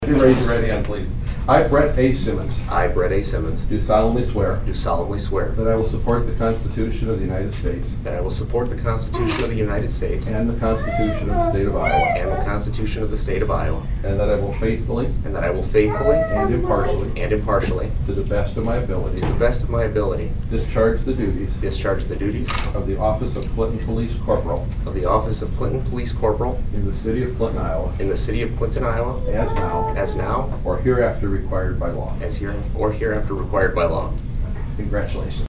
taking the oath